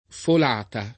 folata [ fol # ta ] s. f.